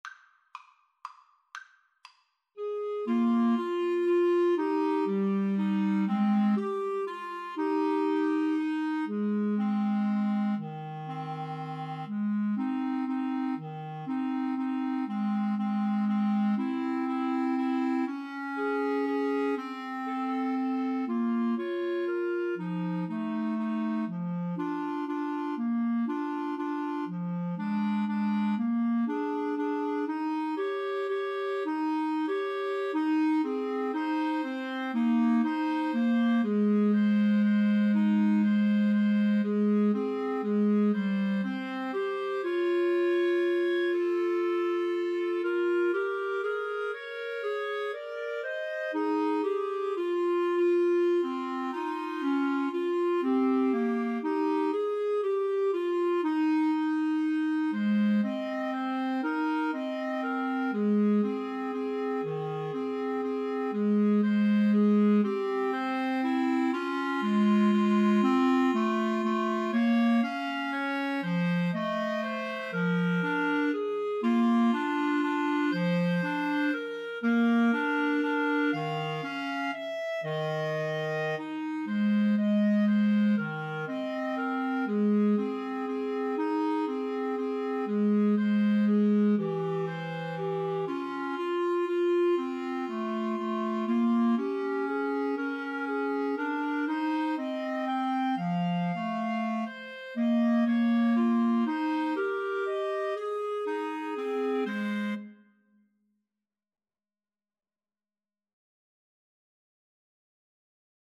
3/4 (View more 3/4 Music)
= 120 Tempo di Valse = c. 120
Jazz (View more Jazz Clarinet Trio Music)